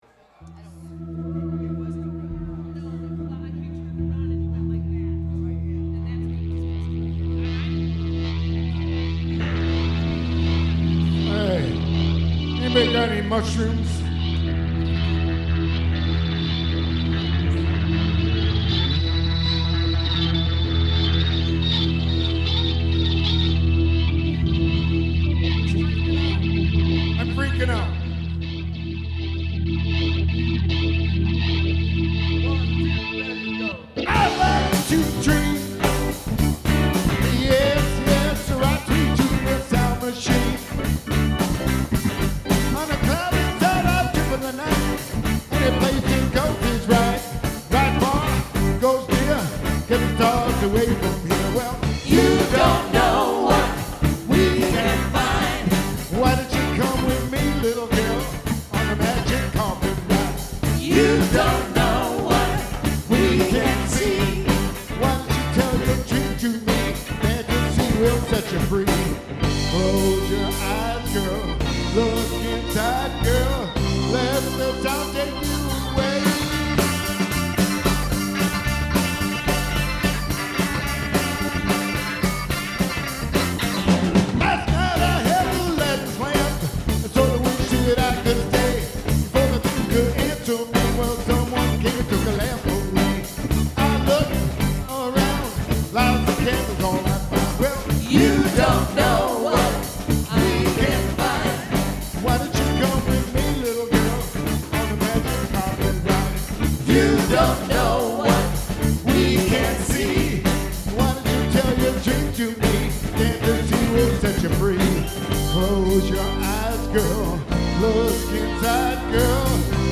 lead vocals, percussion, guitar
keyboards, vocals
saxophone
bass guitar
drums